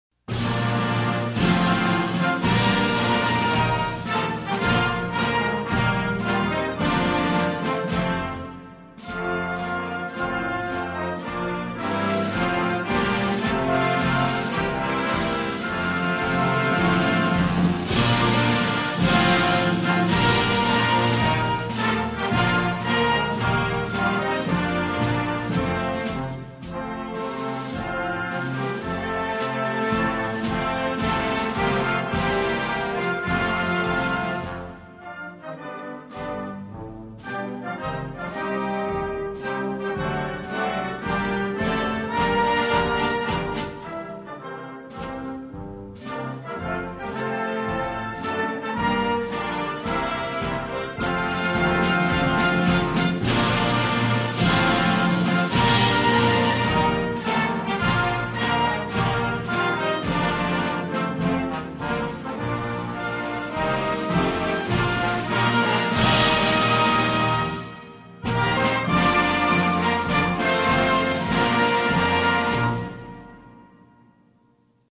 National_anthem_of_the_Bahamas.mp3